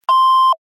Звуки неправильного ответа
На этой странице собрана коллекция звуковых эффектов, обозначающих неправильный ответ или неудачу.
Сигнал при неправильном действии